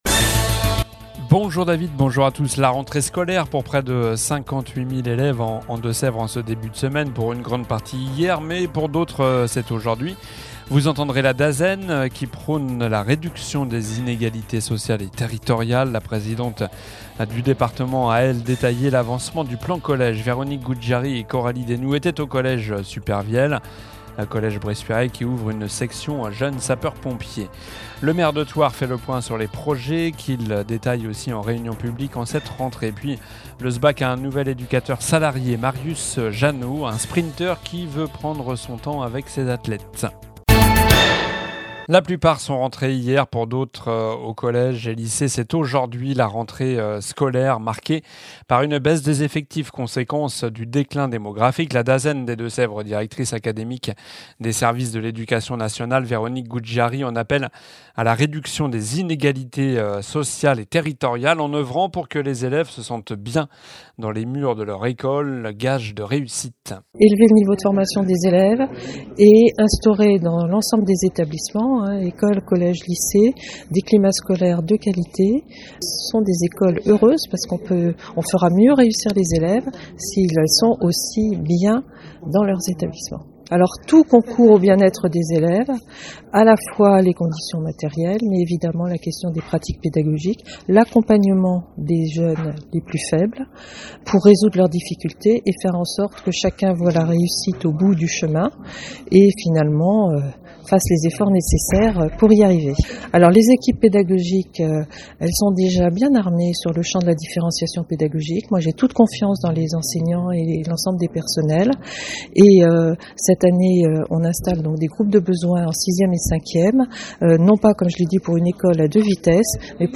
JOURNAL DU MARDI 03 DEPTEMBRE ( MIDI )